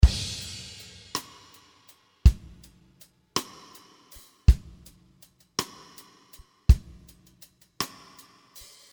In total this loop song contains 33 loops. 13 of them are pure playing on the cymbals. The snare and toms has a lot of reverb because we wanted this loops song To be best for romantic style that will be great with strings and nice chorus guitars.
This 81 bpm 6/8 signature is basically waltz rhythm but in this days
This style of rhythm is for slow rock songs and romantic songs.